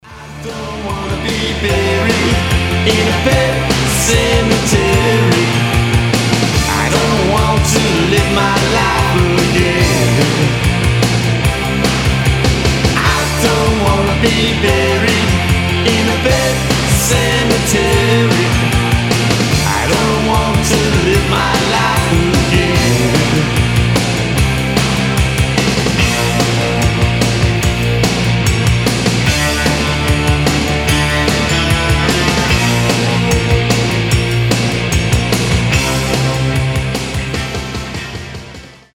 gothic rock , панк-рок